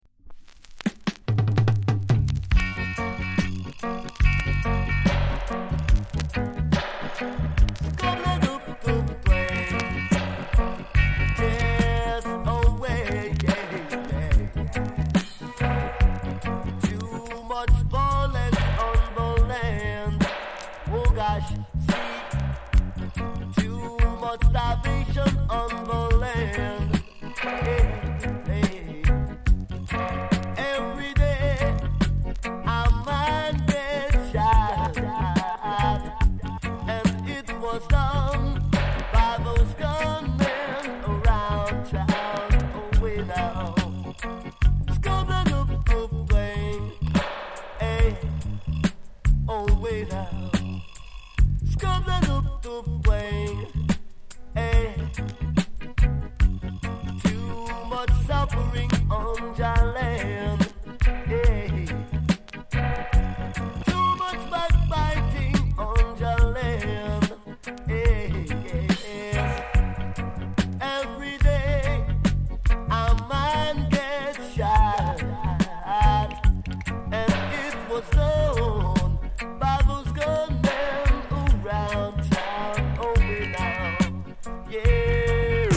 高品質 ONE DROP～ROOTS
概ね美盤ですが、両面 あたまにパチつく箇所あり。